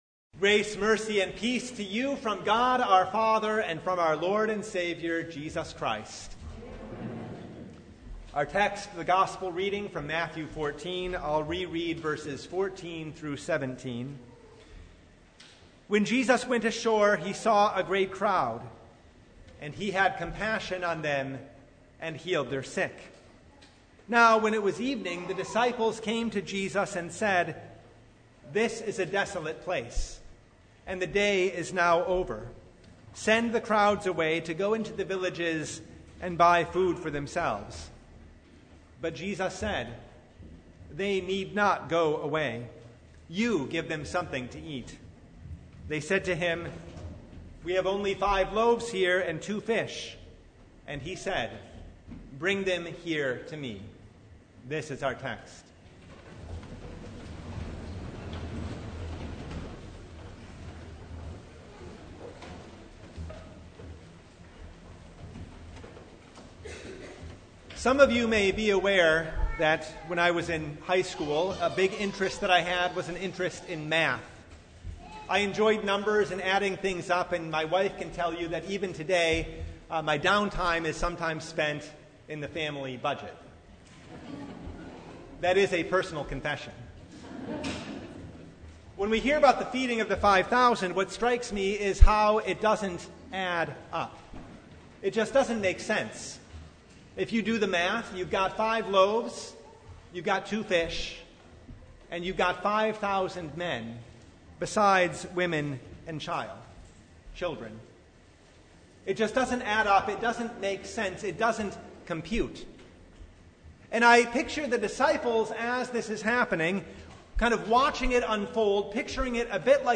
Matthew 14:13-21 Service Type: Sunday Five loaves